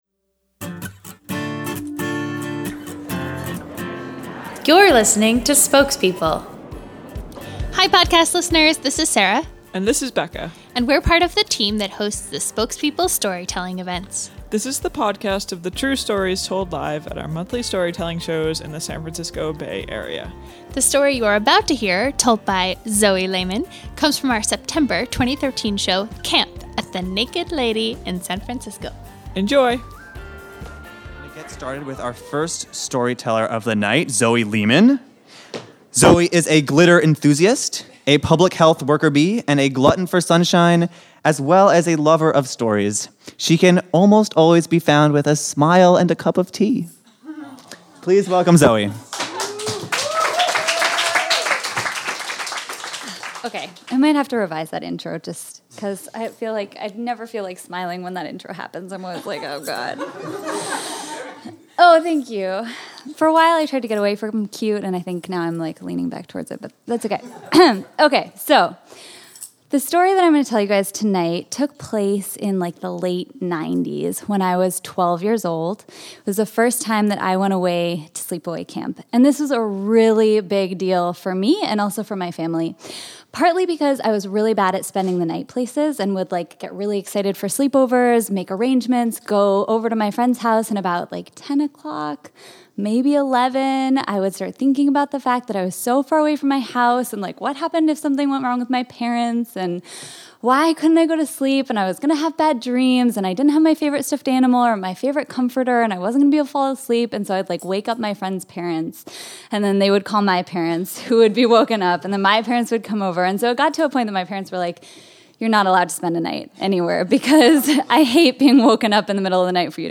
Even an appreciation for Cherry Garcia ice cream gets her nowhere with this less than merry prankster. This story was told live at our September 2013 show, “Camp.”